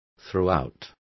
Complete with pronunciation of the translation of throughout.